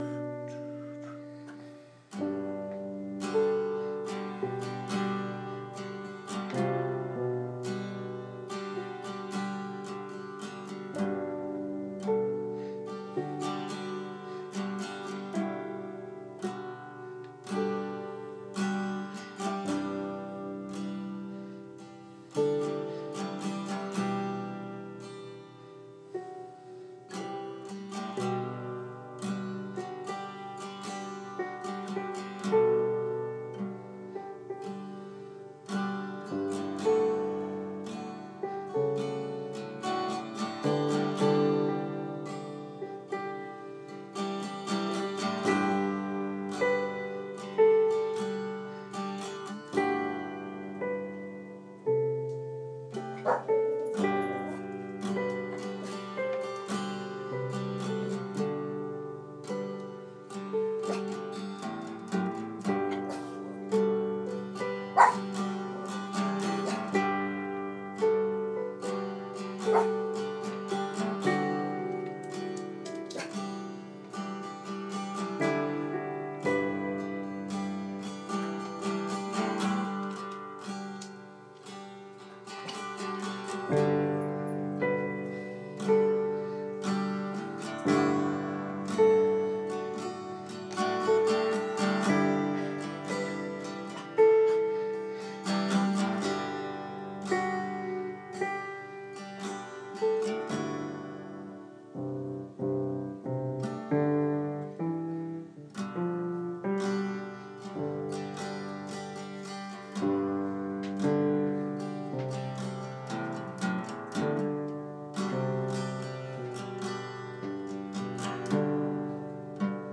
G 12 Bars